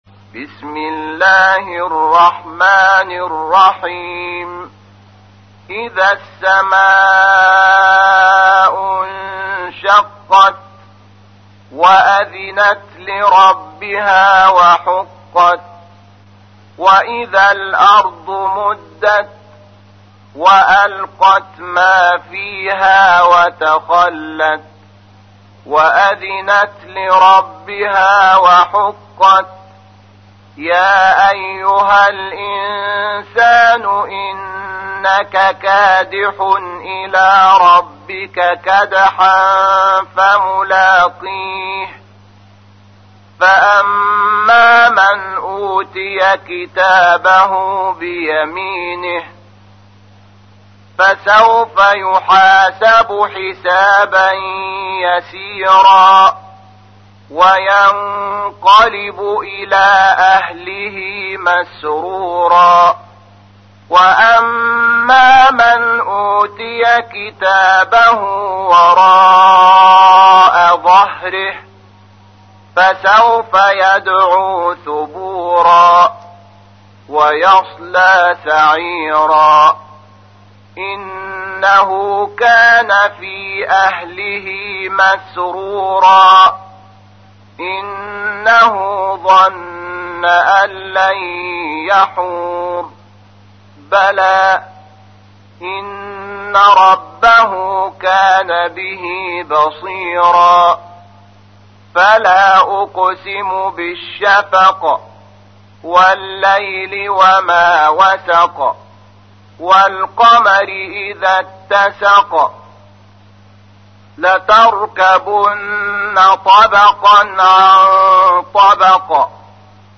تحميل : 84. سورة الانشقاق / القارئ شحات محمد انور / القرآن الكريم / موقع يا حسين